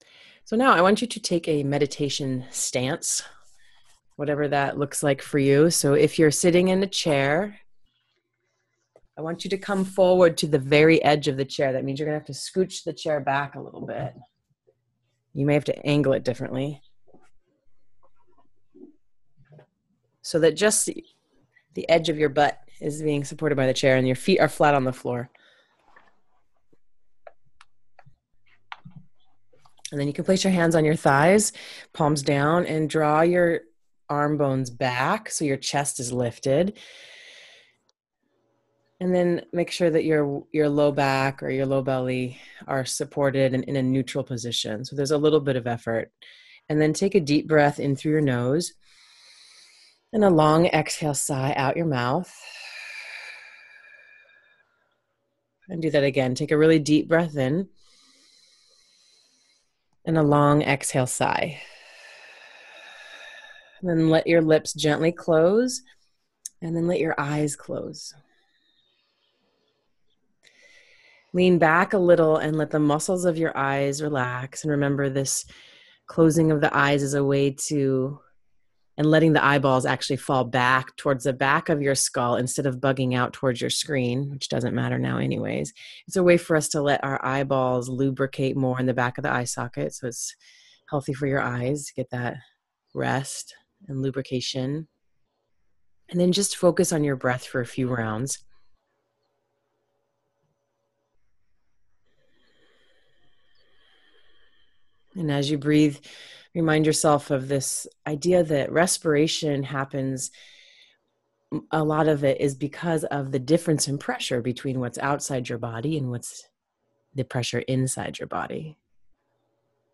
Space Meditation
Perspective_Audio_Meditation.m4a